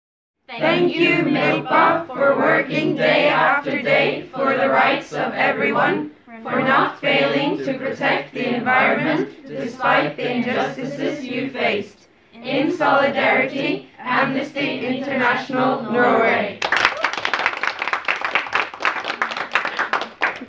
kliknite za solidarnostno sporočilo, ki so ga posneli mladi aktivisti na Norveškem).